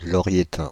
Ääntäminen
France (Île-de-France): IPA: /lɔ.ʁjə.tɛ̃/